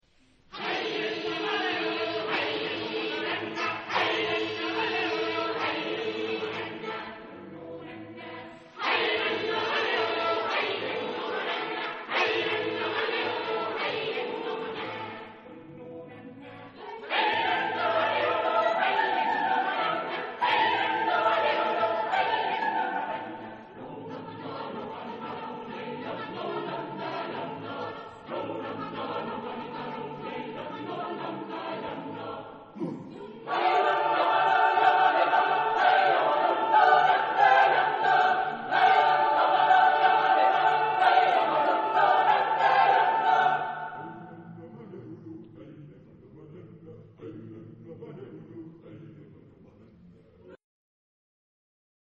Text in: onomatopoeia
Genre-Style-Form: Secular ; Joik
Type of Choir: SSSAATTTBBB  (11 mixed voices )
Discographic ref. : Internationaler Kammerchor Wettbewerb Marktoberdorf